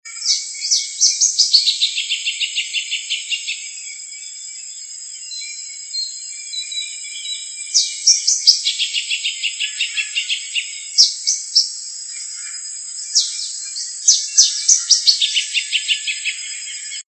Southern Yellowthroat (Geothlypis velata)
Sex: Male
Life Stage: Adult
Country: Argentina
Location or protected area: Villa Paranacito
Condition: Wild